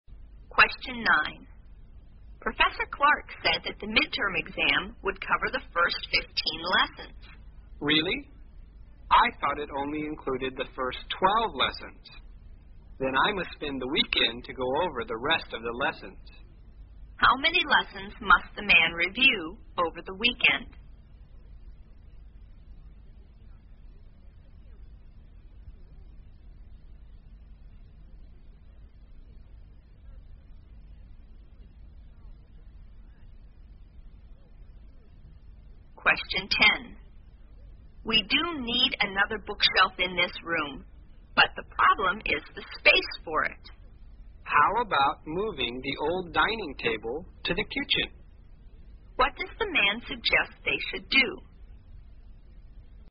在线英语听力室183的听力文件下载,英语四级听力-短对话-在线英语听力室